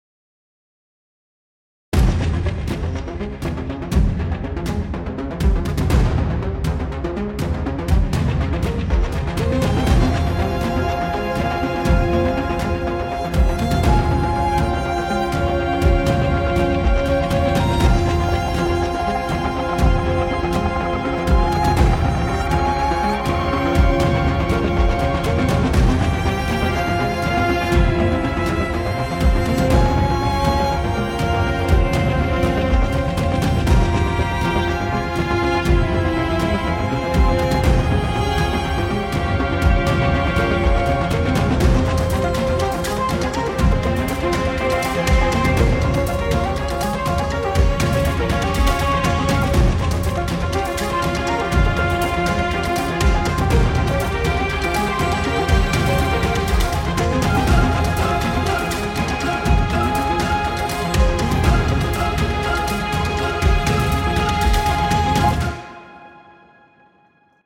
Thriller